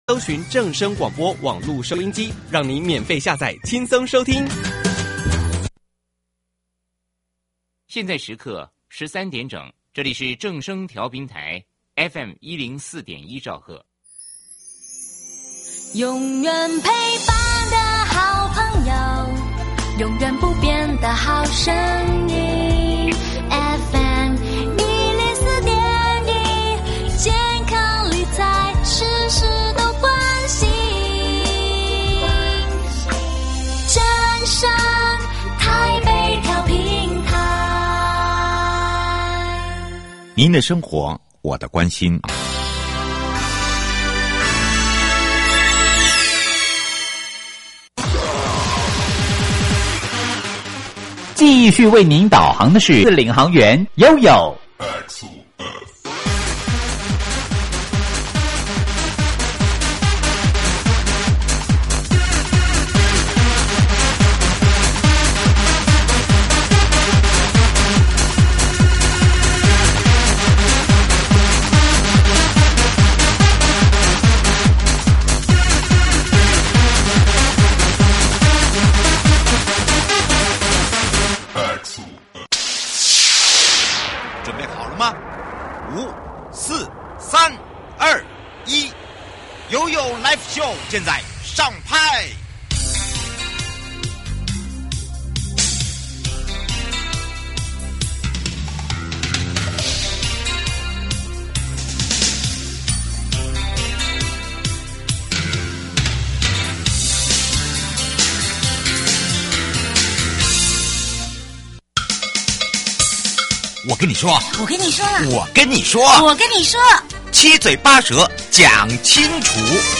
今天邀請到臺中市政府建設局陳大田局長，請局長來幫大家說明前瞻基礎建設計畫的相關內容，我知道內政部的前瞻基礎建設計畫也已經推動多年，這個計畫當中跟民眾切身相關的子計畫有哪一些?請局長跟我們分享。